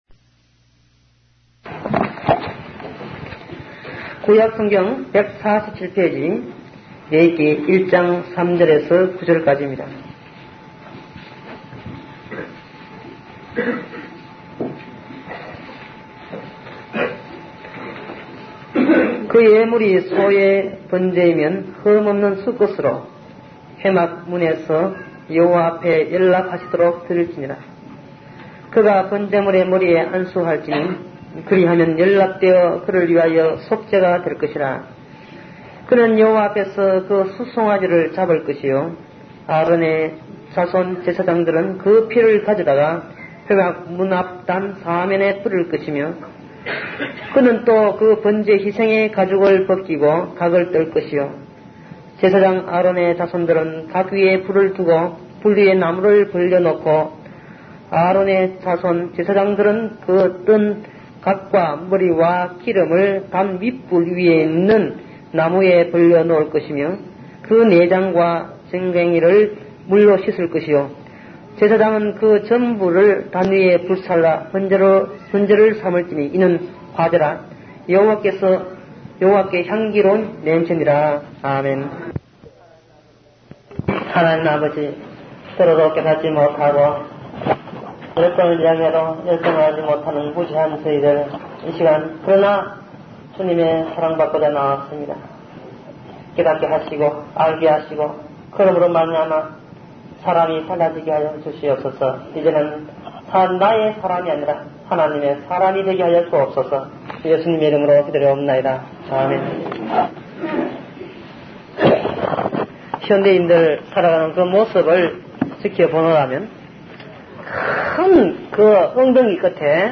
'구약 설교, 강의